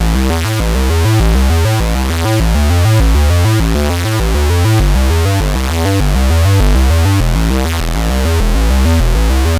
Arp Notes
• Wavetable Rate: 36 (slow)
mbsid_tutor_arp_notes.mp3